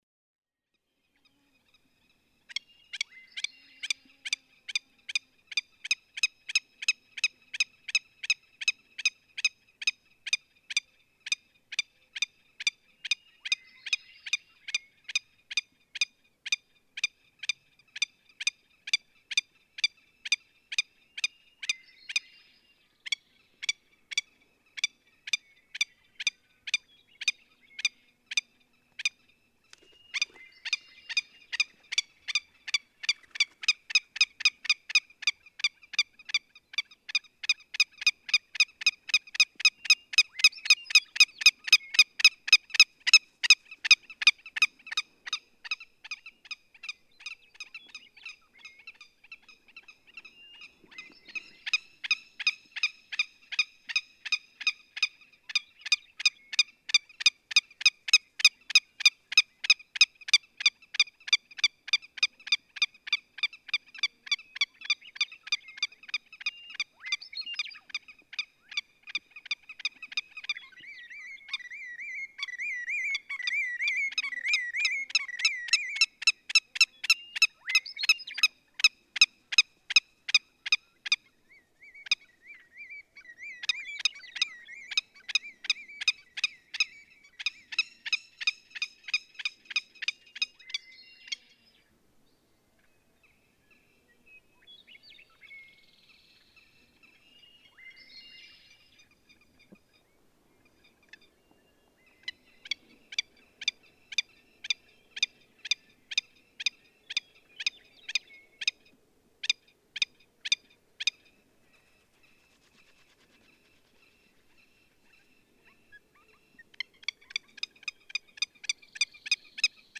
Willet
Sharp klik klik klik klik calls by what seemed to be an agitated bird flying about its territory, with at least one more bird also calling in the background.
Malheur National Wildlife Refuge, Burns, Oregon.
538_Willet.mp3